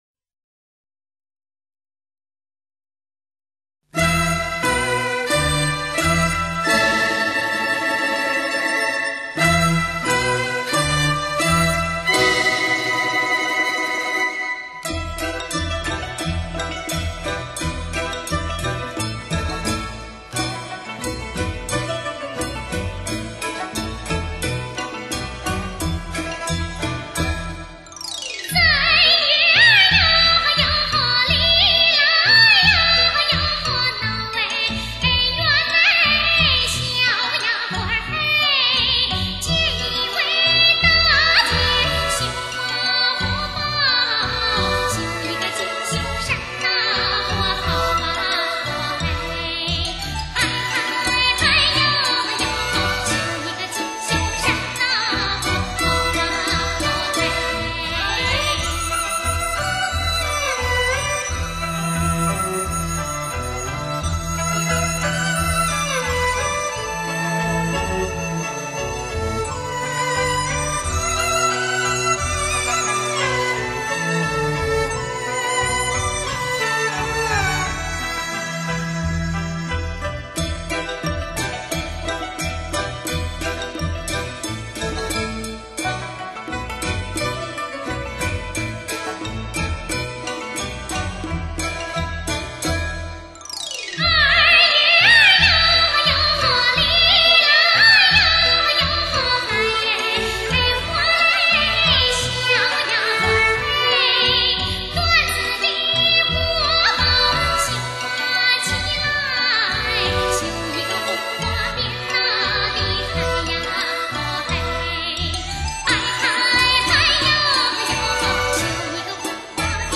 [30/12/2009]绣荷包(湖北民歌)-吴碧霞